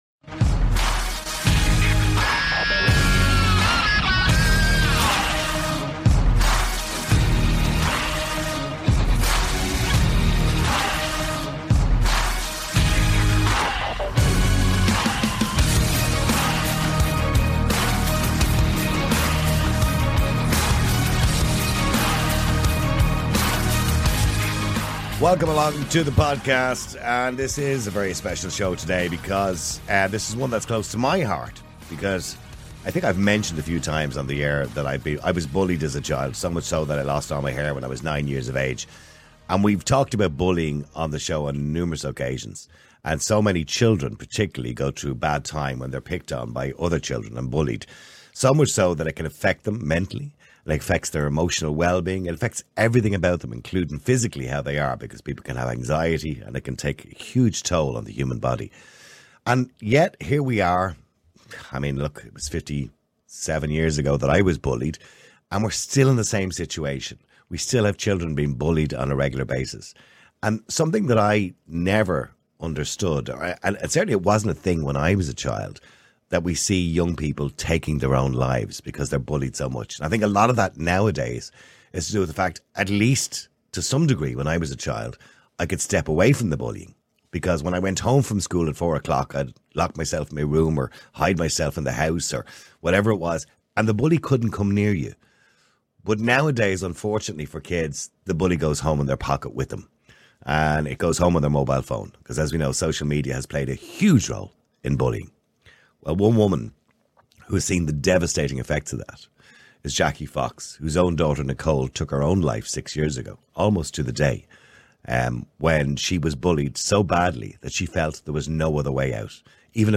In this emotionally charged interview